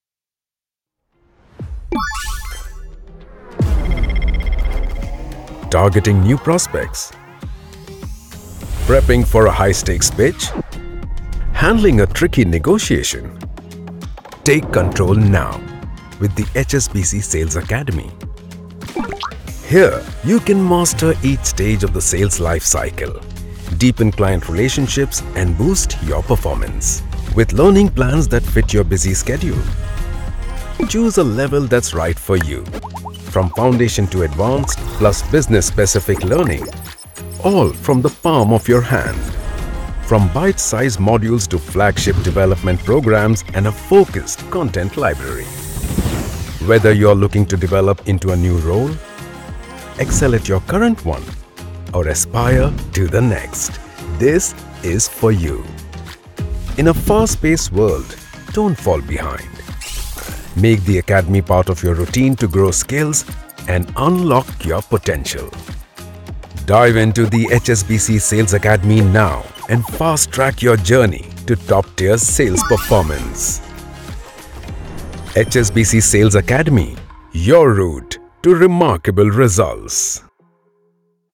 Neutral / Indian/ British/ Arabic / Russian
Middle Aged
Equipped with a professional home studio, Passionately enjoy voice over projects of all kinds,